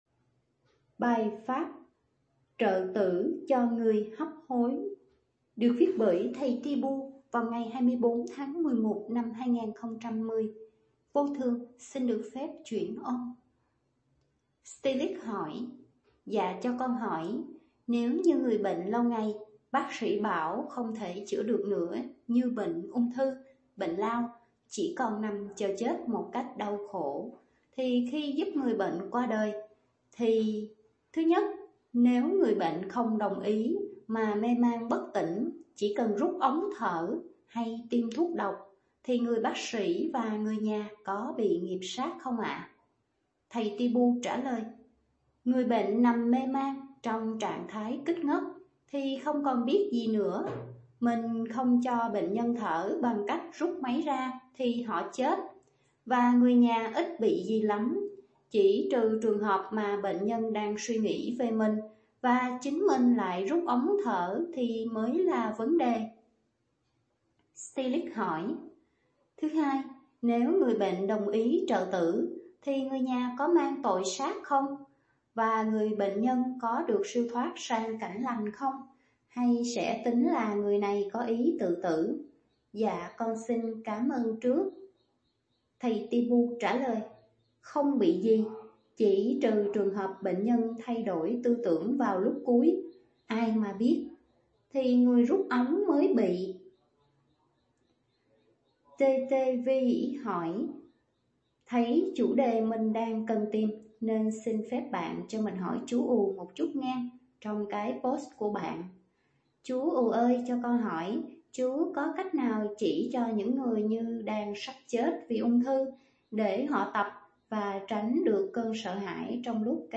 chuyển âm